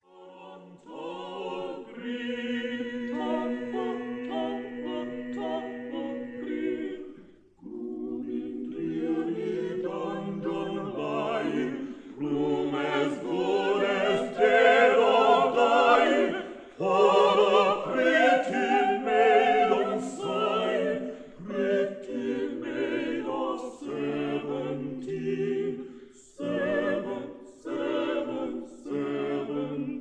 tenor
bass
contralto
soprano